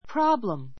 prɑ́bləm